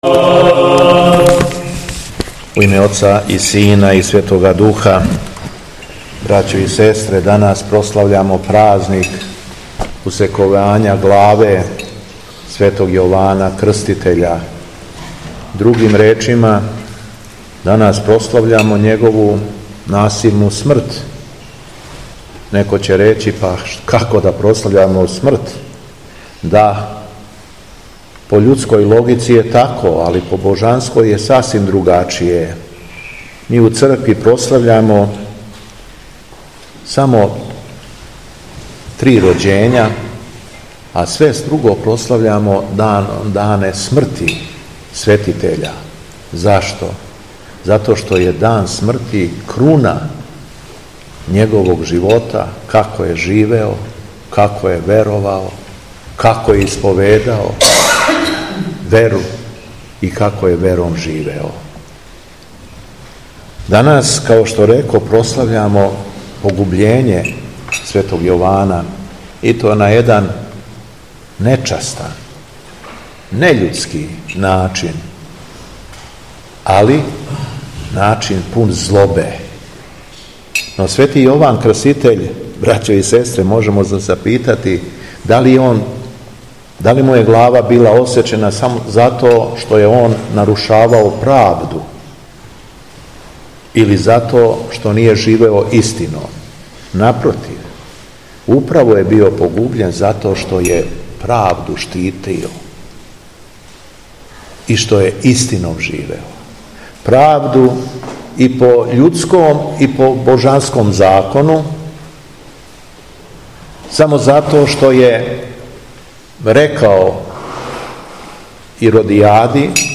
У четвртак, 11. септембра 2025. године, када се наша Света Црква молитвено сећа и празнује усековање главе Светог Јована Крститеља и Светог Григорија епископа рашког, његово Високопреосвештенство Митрополит шумадијски Господин Јован, служио је Свету Архијерејску Литургију у манастиру Каленићу.
Беседа Његовог Високопреосвештенства Митрополита шумадијског г. Јована